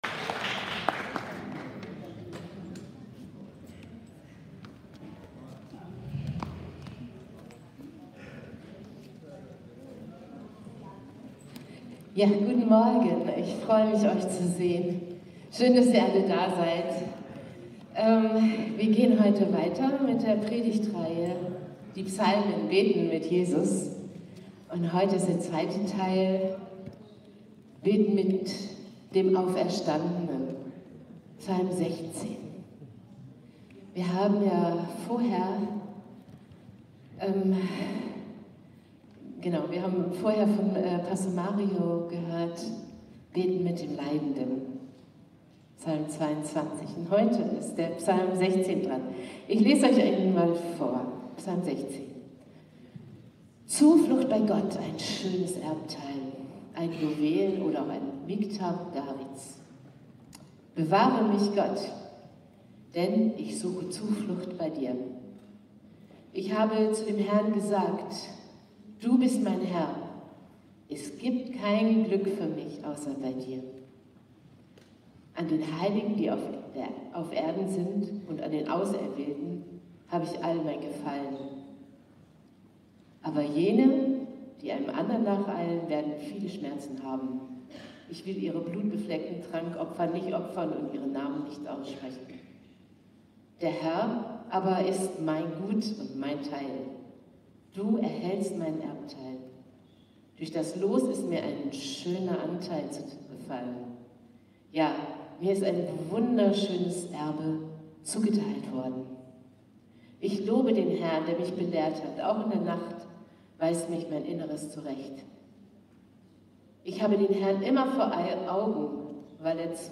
Predigtreihe: Die Psalmen – Beten mit Jesus